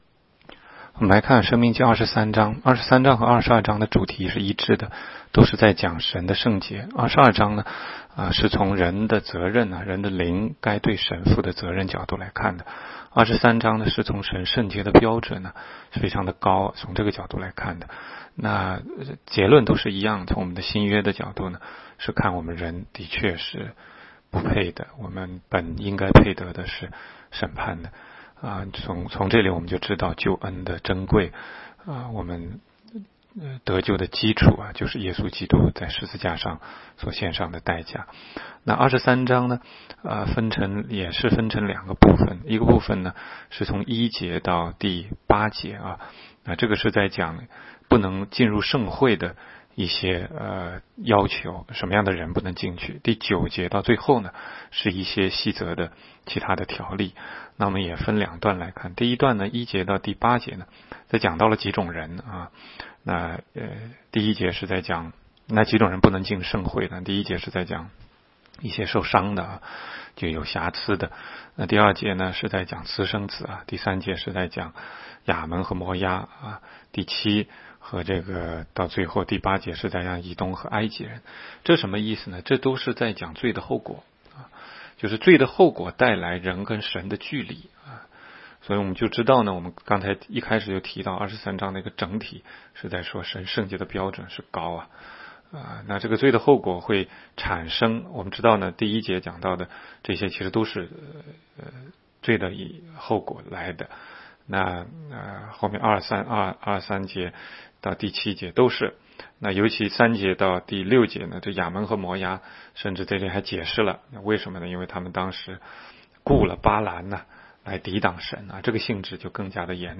16街讲道录音 - 每日读经-《申命记》23章